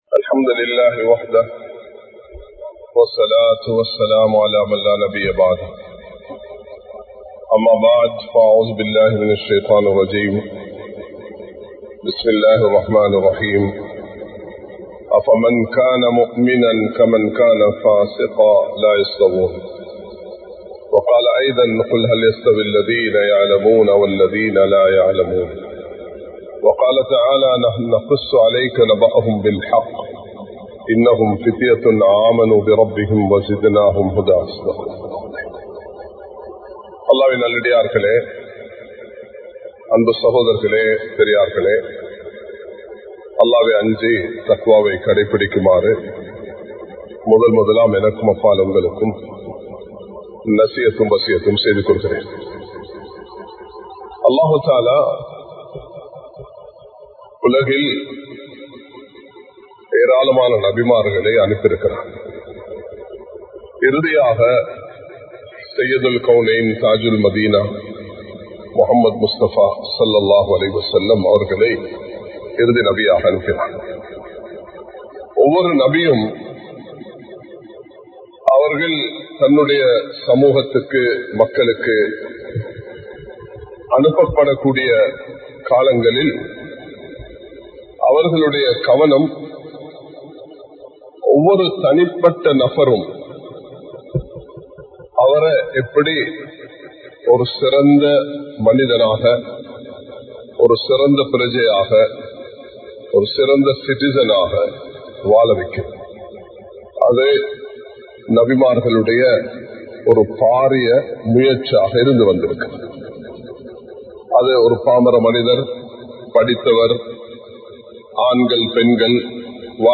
உண்மையான முஸ்லிம் | Audio Bayans | All Ceylon Muslim Youth Community | Addalaichenai
Kollupitty Jumua Masjith